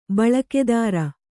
♪ baḷakedāra